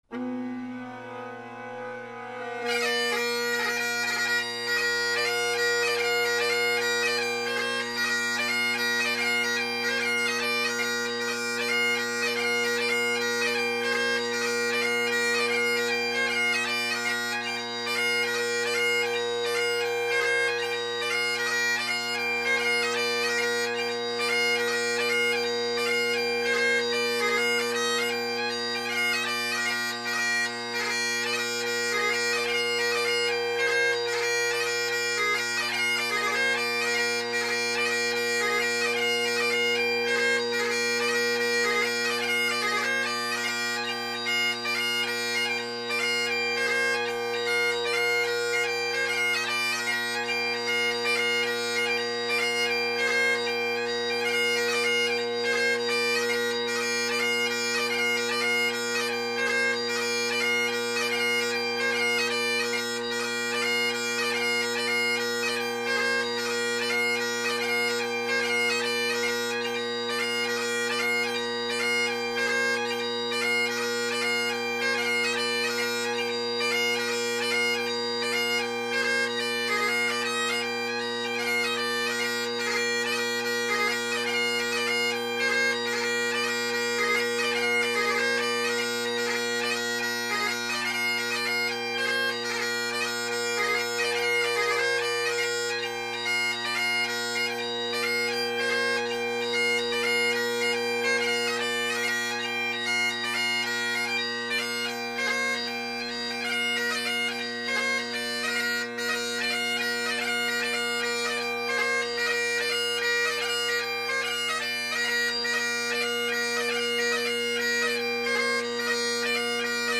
This is a 4 part reel that’s pretty easy overall, with a beefy 3rd part that incorporates 2 low A taorluaths and 1 bubbly note from C to B. For Grade IV players, I’ve got a 2 part reel for you: Kate Dalrymple.